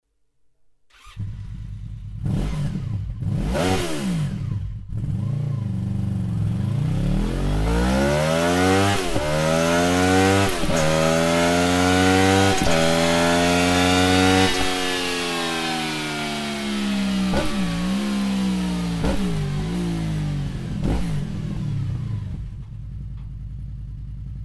Sound Akrapovic Slip-On ohne dB Killer